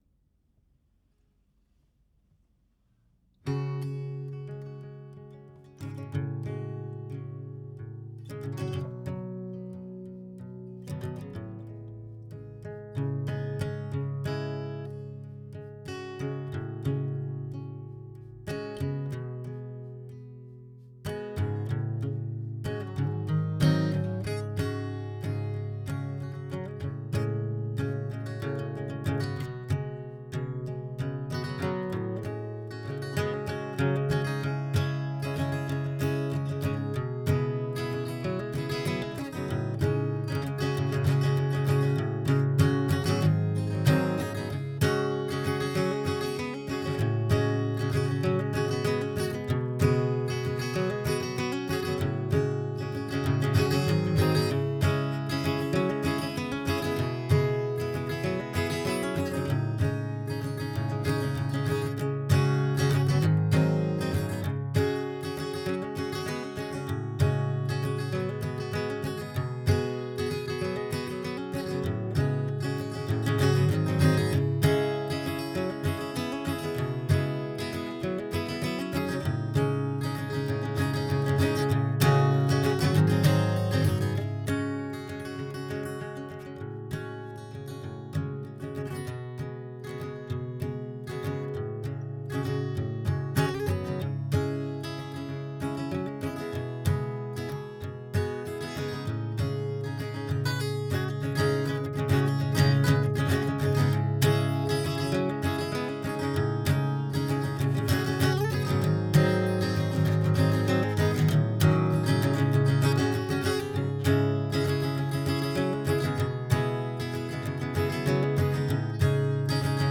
Original tracks and instrumentals for licensing available.